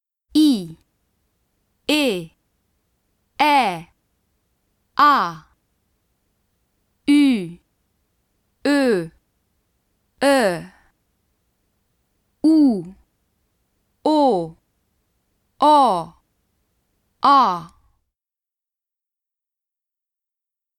トップページ > 綴り字と発音 > ２ 母音 ２ 母音 [y]：唇を[u]のように突き出して[i]を発音。 [ø]：唇を[o]のように突き出して[e]を発音。 [œ]：唇を[ɔ]のように突き出して丸く開き[ɛ]を発音。 [ə]：[ø]を弱く発音。